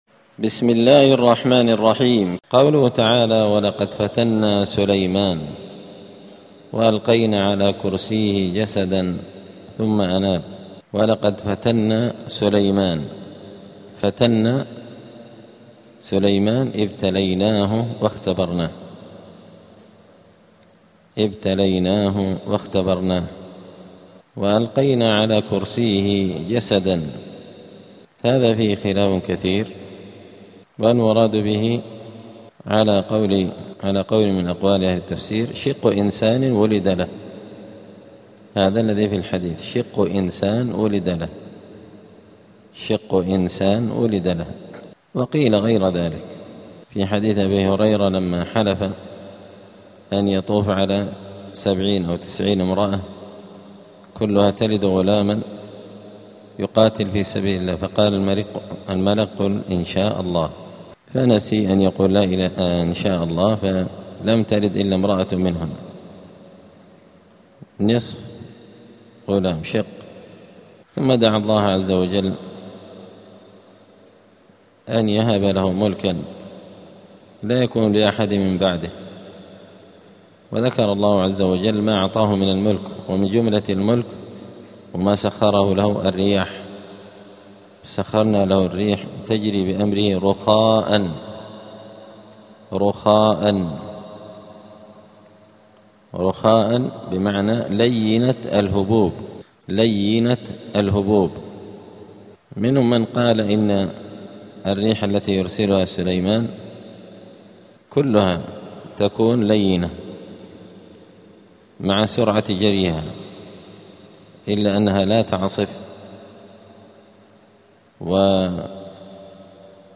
الأثنين 28 ربيع الثاني 1447 هــــ | الدروس، دروس القران وعلومة، زبدة الأقوال في غريب كلام المتعال | شارك بتعليقك | 7 المشاهدات
دار الحديث السلفية بمسجد الفرقان قشن المهرة اليمن